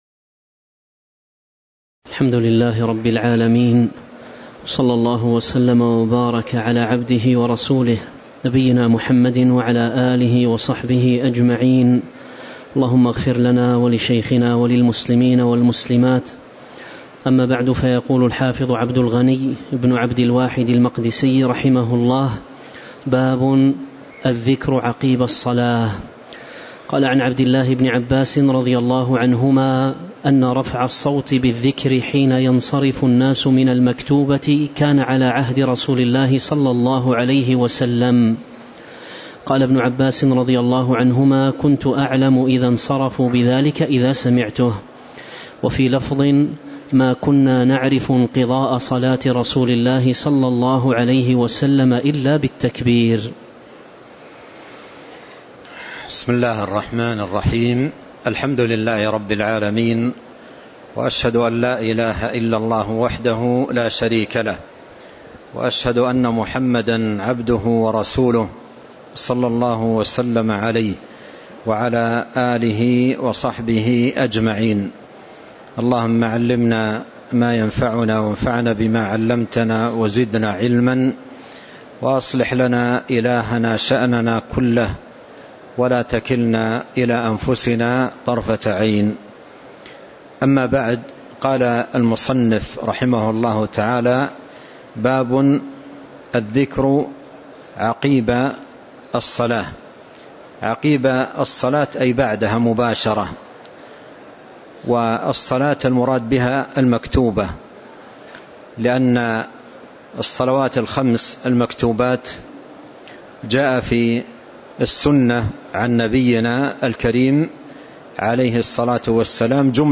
تاريخ النشر ١١ جمادى الأولى ١٤٤٤ هـ المكان: المسجد النبوي الشيخ